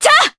Juno-Vox_Attack2_jp.wav